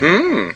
The custom notification sound is (pic related) going "HmMmmm".